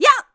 FSA_Link_SwordSlash1Link's sword-slash11 KBMono, 16 KHz
FSA_Link_SwordSlash1.wav